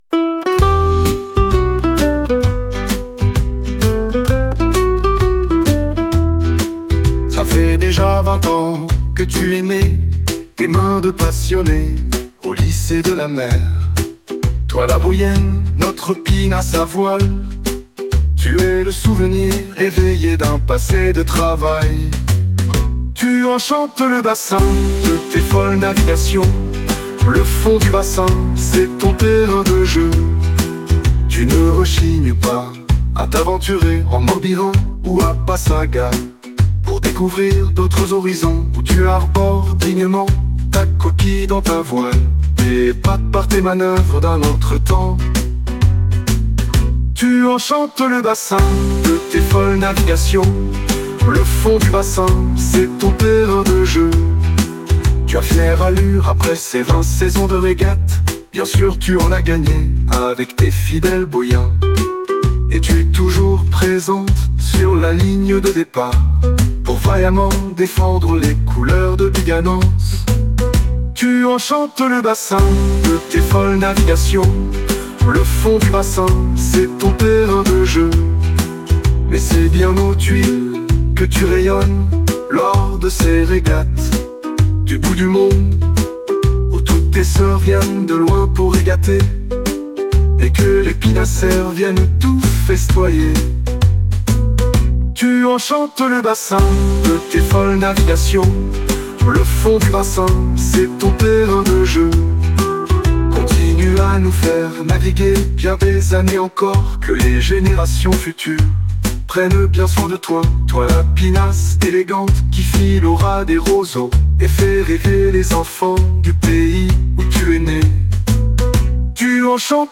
La chanson des 20 ans de la Boïenne permet d’entamer une grande soirée auberge espagnole pour festoyer comme il se doit aux Tuiles.